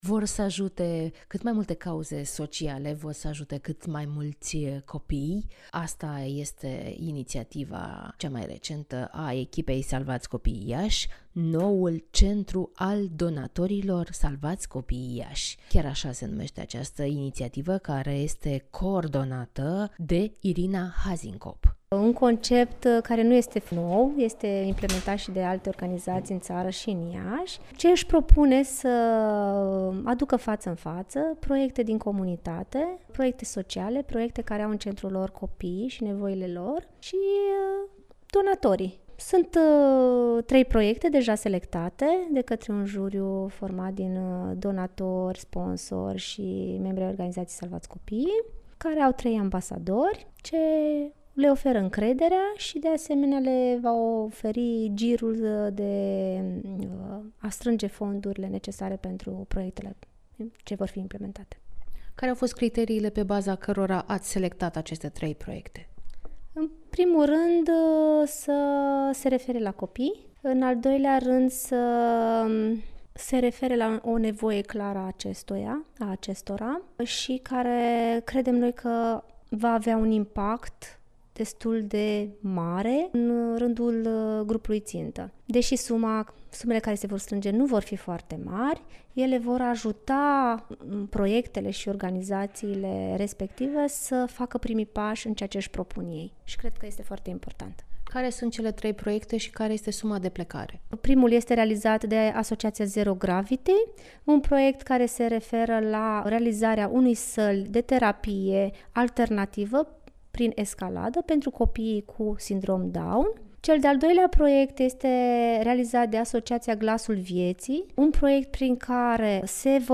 ( INTERVIU) La Iași se va desfășura primul eveniment creat prin intermediul Cercului Donatorilor Salvați Copiii Iași
La Iasi se va desfasura primul eveniment creat prin intermediul Cercului Donatorilor Salvati Copiii Iasi. Care este rolul si care sunt proiectele sustinute veti afla din interviul oferit pentru Radio Iasi.